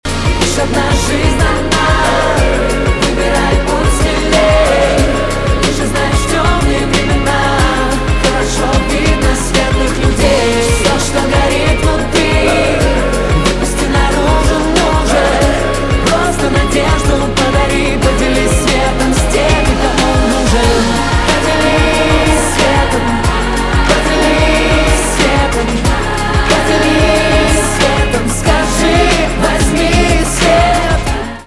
поп
хор , битовые